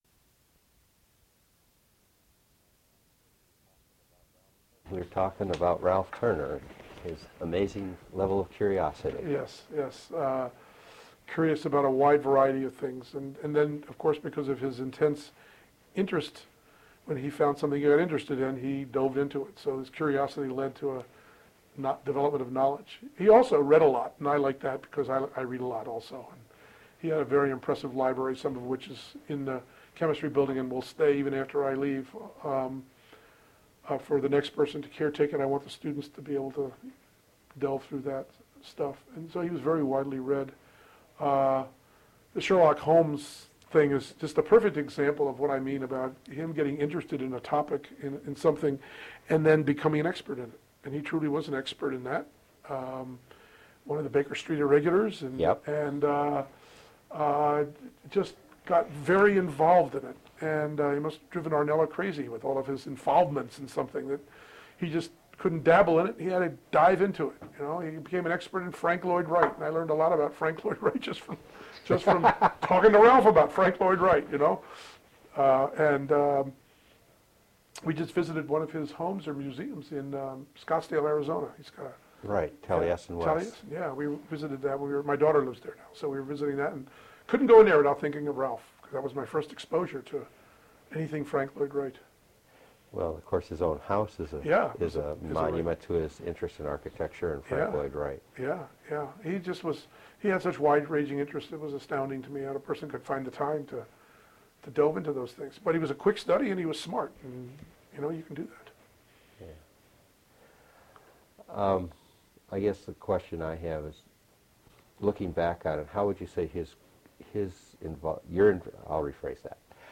Original Format: Audiocassettes
Sesquicentennial Oral History Project